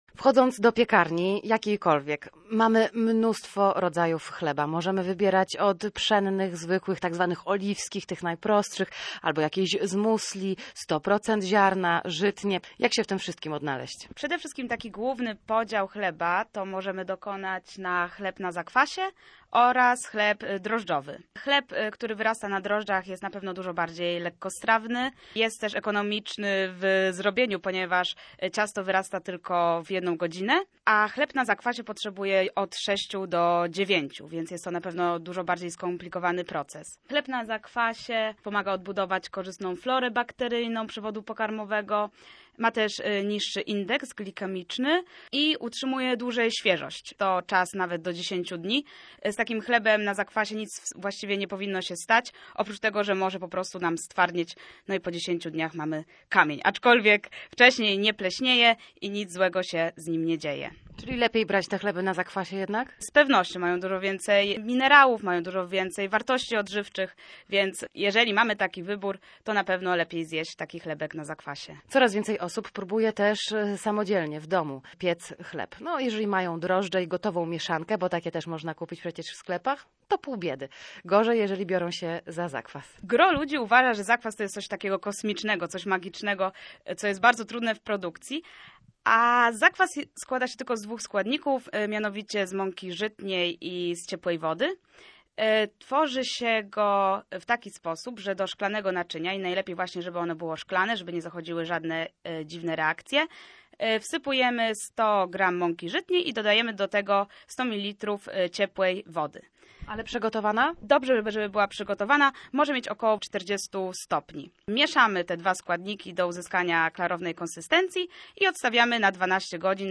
W audycji Gotuj się na weekend skupiły się na jego przygotowaniu.